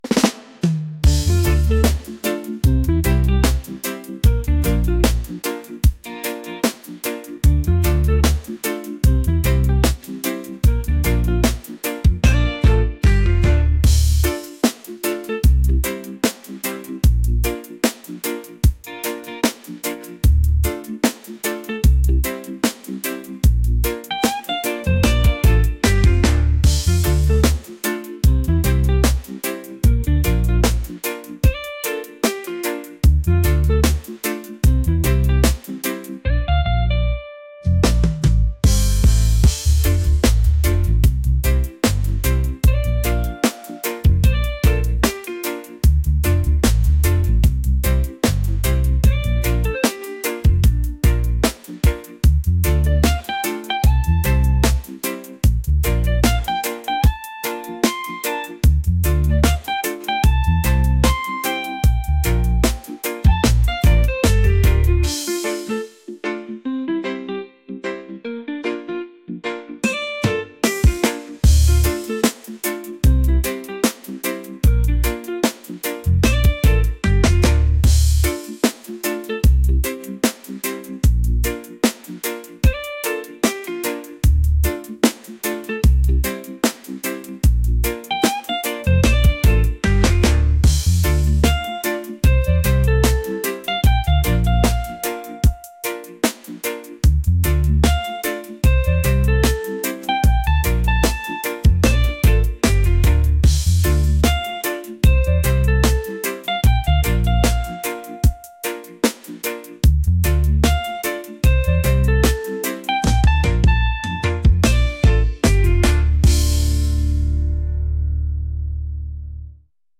relaxed | reggae | soulful